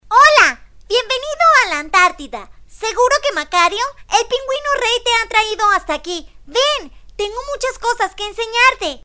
西班牙语儿童时尚活力 、亲切甜美 、素人 、女绘本故事 、动漫动画游戏影视 、150元/百单词女西09 西班牙语女声 女童 时尚活力|亲切甜美|素人